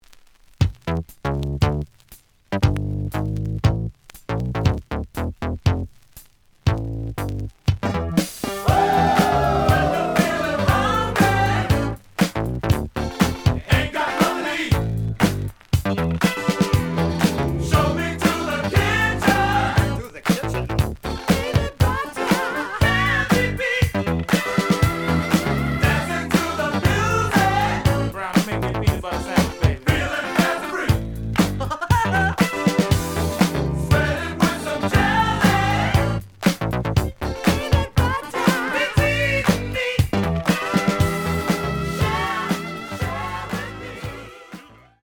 The audio sample is recorded from the actual item.
●Genre: Funk, 70's Funk
Slight edge warp. But doesn't affect playing. Plays good.)